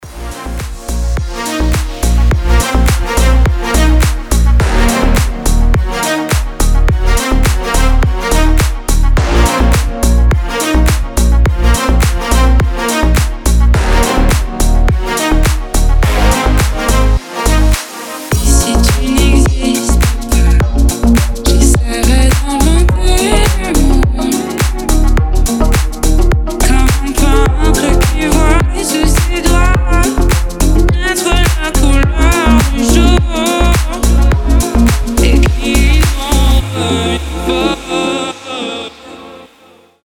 • Качество: 320, Stereo
красивые
deep house
женский голос
Cover
виолончель